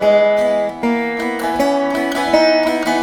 158A VEENA.wav